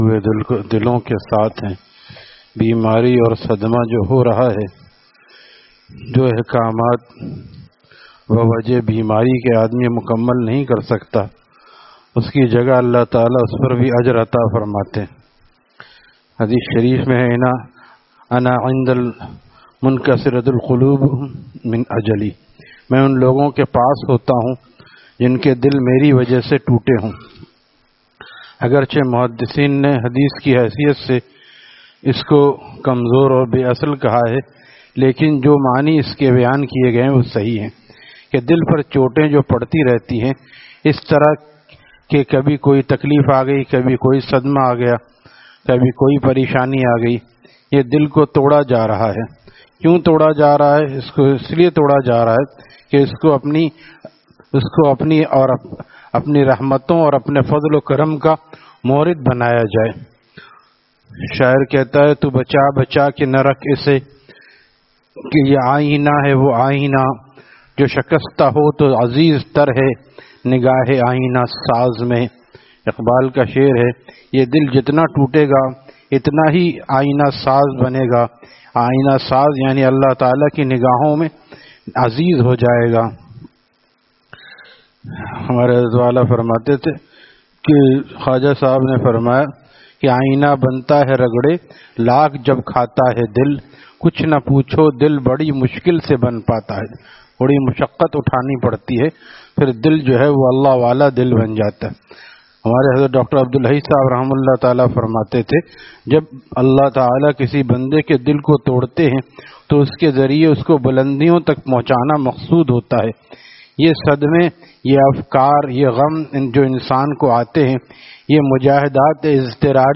Taleem After Fajar at Jamia Masjid Gulzar e Mohammadi, Khanqah Gulzar e Akhter, Sec 4D, Surjani Town